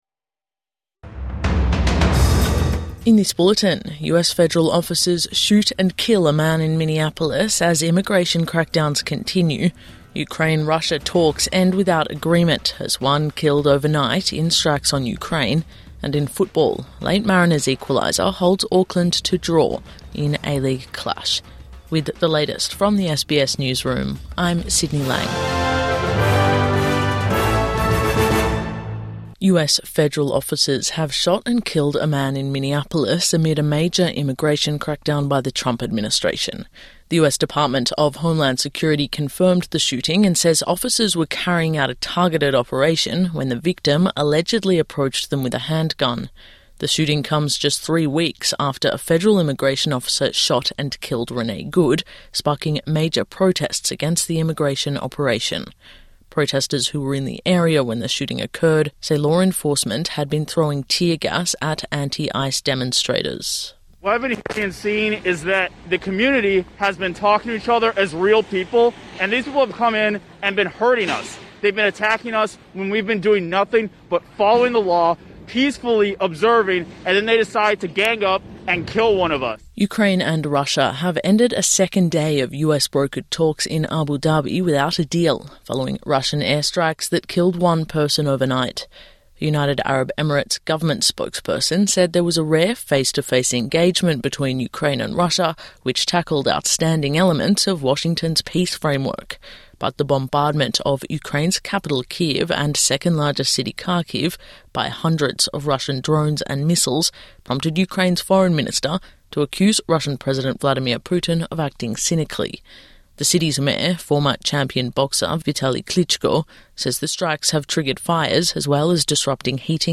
US federal officers shoot and kill a man in Minneapolis | Morning News Bulletin 25 January 2026